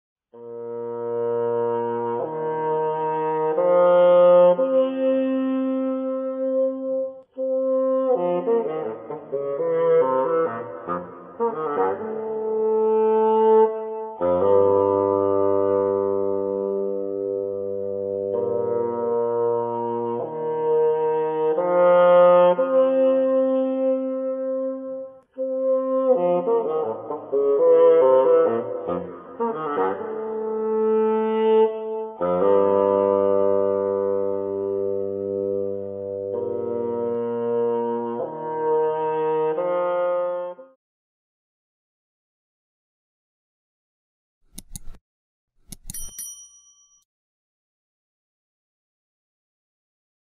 VIENTO MADERA
Sonido+De+Fagot (audio/mpeg)
FAGOT